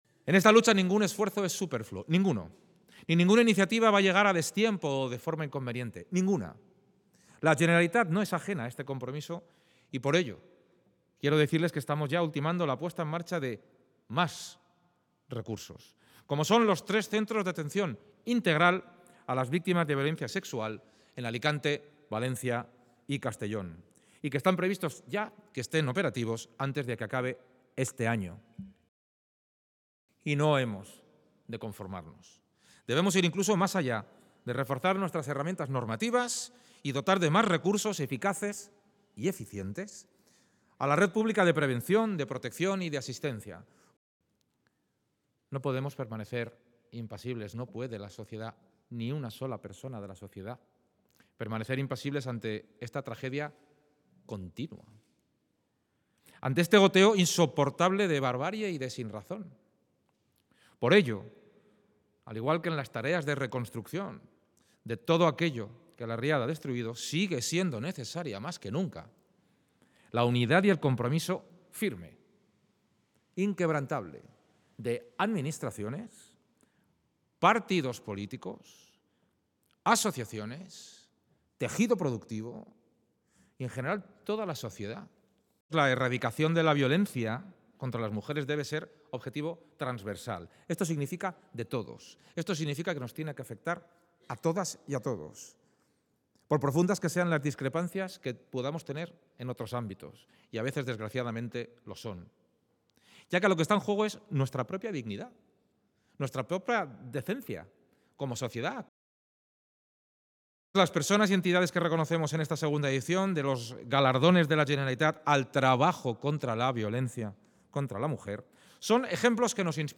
Así lo ha manifestado el president durante el acto institucional celebrado en el marco del Día Internacional para la Eliminación de la Violencia contra la Mujer, que se conmemora cada 25 de noviembre, en el que también han participado la vicepresidenta primera y consellera de Servicios Sociales, Igualdad y Vivienda, Susana Camarero.